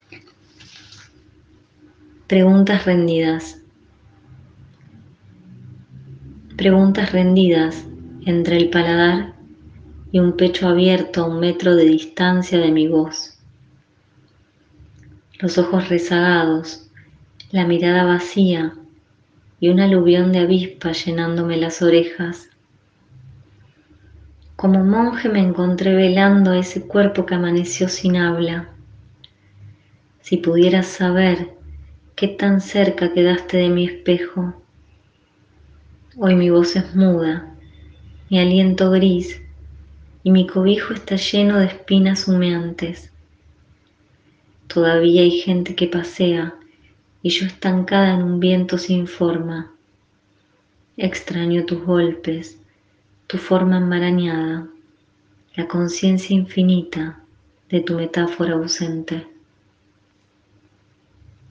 nos lee uno de sus poemas «Preguntas rendidas» de su libro «Conjuros entre flores y furia»